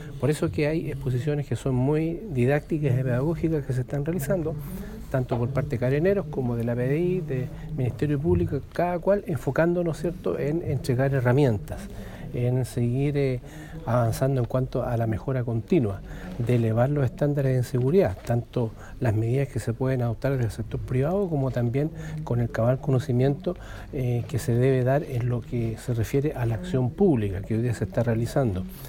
La actividad de diagnóstico, diálogo y capacitación tuvo una serie de exposiciones de autoridades, representantes de las policías y la Fiscalía, en busca de entregar herramientas para mejorar la seguridad en los distintos comercios de la región, como destacó el Intendente subrogante, Juan Carlos Gallardo